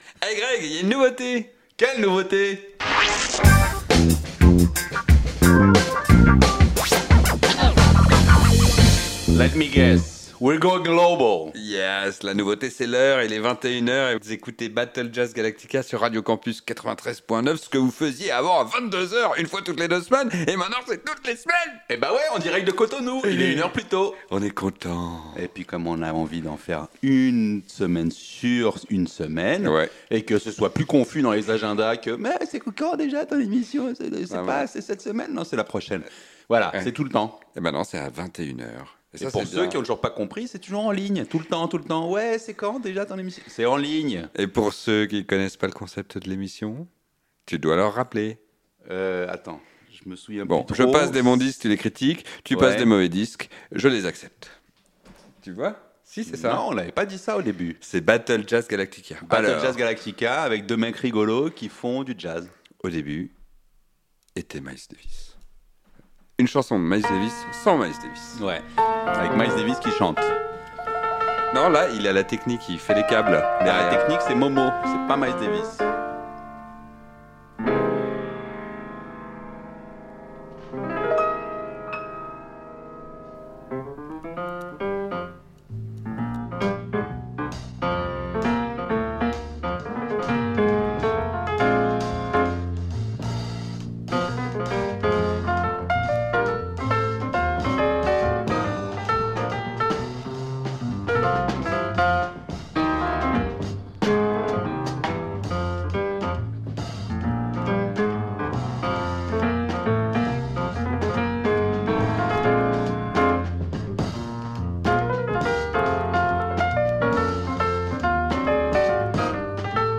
Classique & jazz